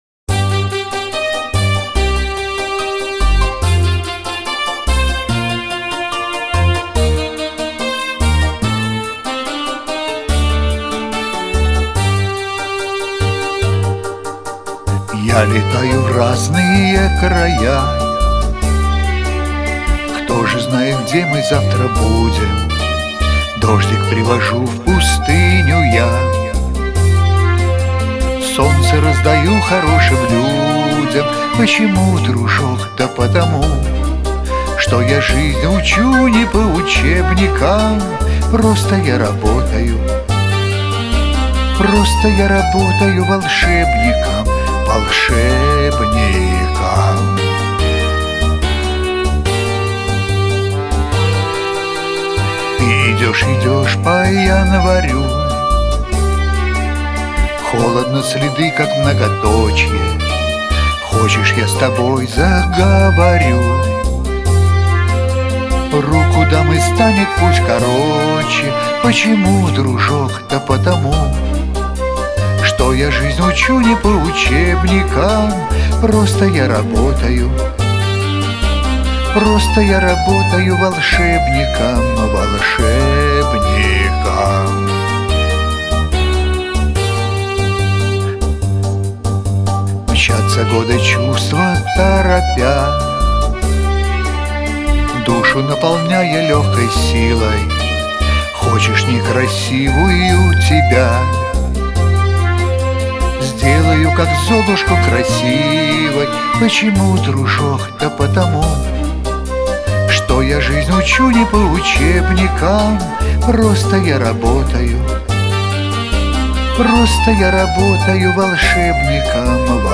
Справа - чистенькая запись. слухабельная smeh.